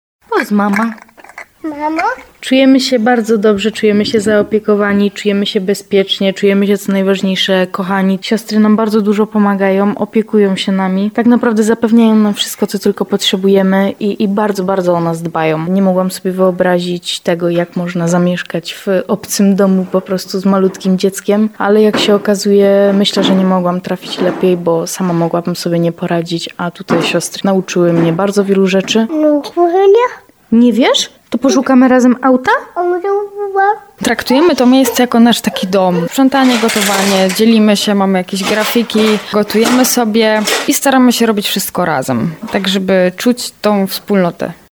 Traktujemy to miejsce jak nasz dom, czujemy się tutaj bardzo dobrze – mówią samotne matki, które znalazły schronienie w Tarnowie.